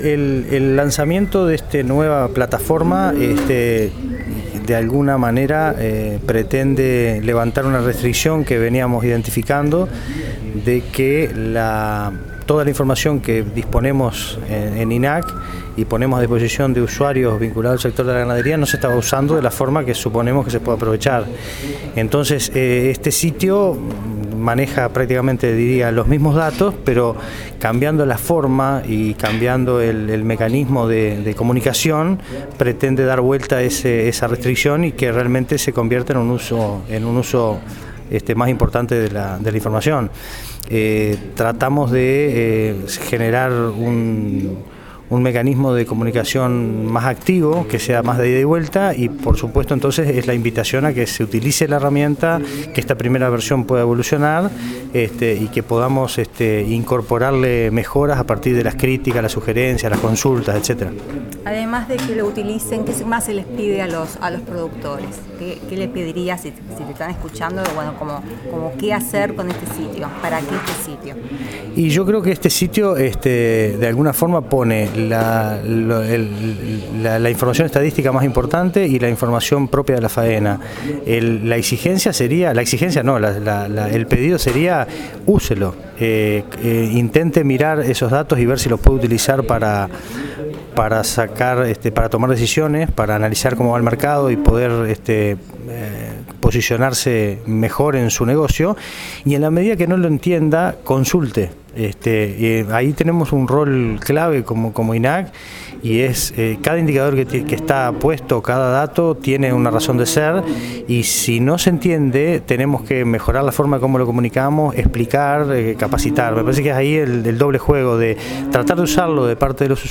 Entrevista-